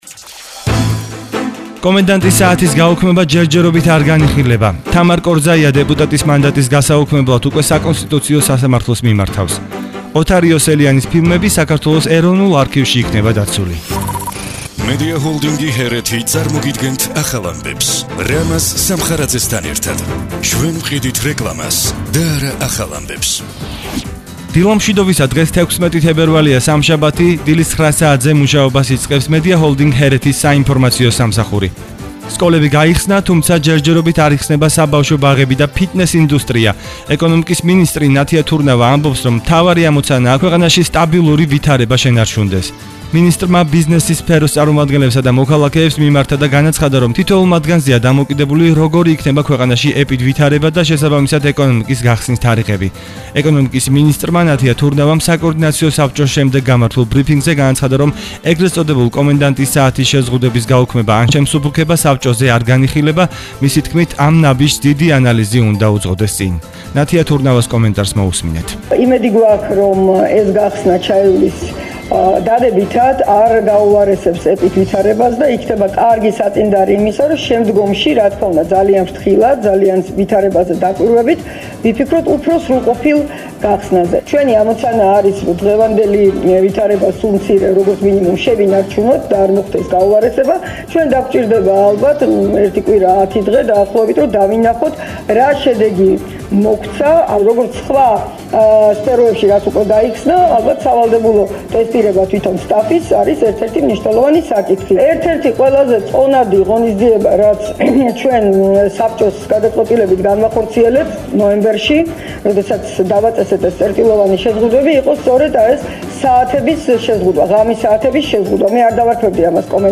ახალი ამბები 9:00 საათზე –16/02/21 - HeretiFM